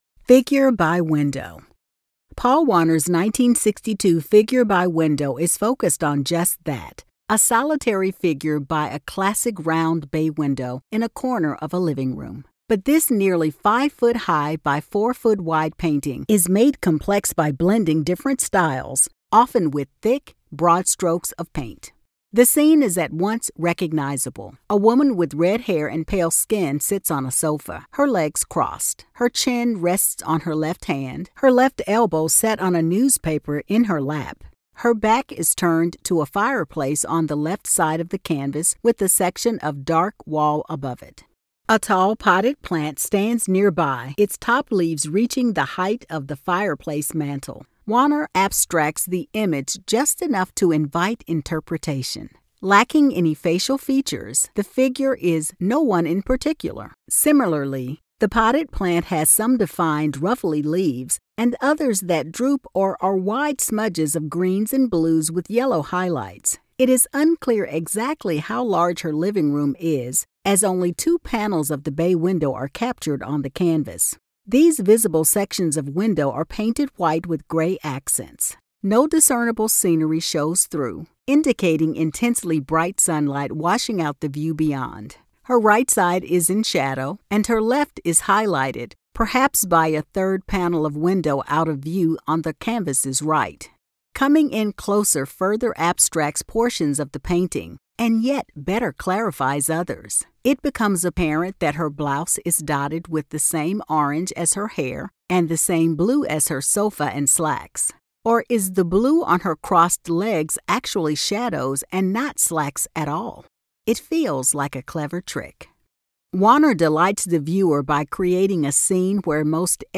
Audio Description (02:43)